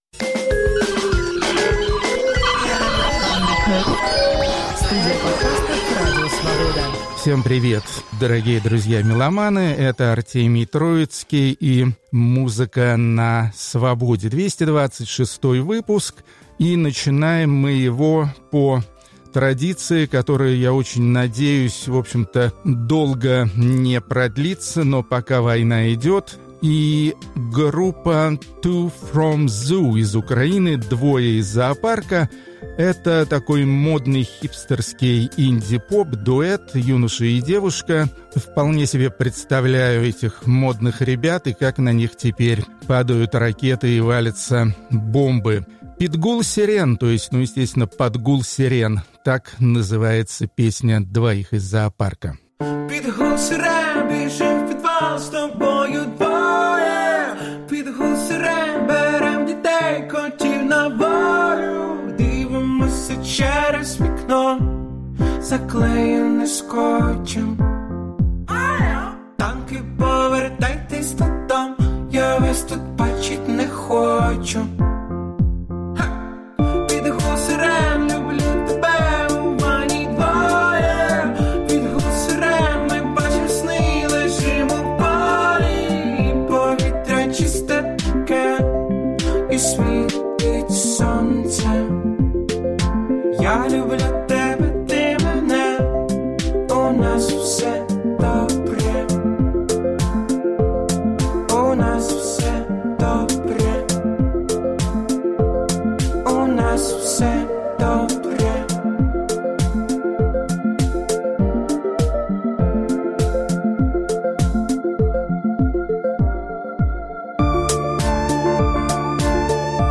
Хедлайнеры двести двадцать шестого выпуска подкаста и радиопрограммы "Музыка на Свободе" – исполнители разных жанров и направлений (но всё близко к этнике) из Греции.